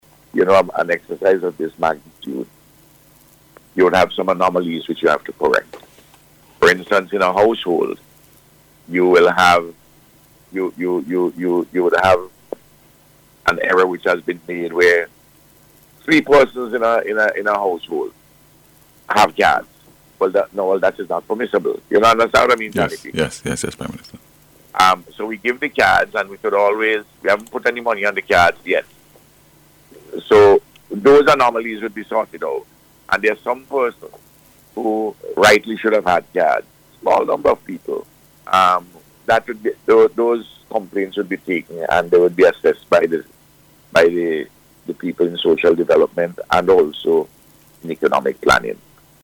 Speaking on NBC’s Face to Face programme yesterday, the Prime Minister outlined the locations where cards would be distributed today and tomorrow.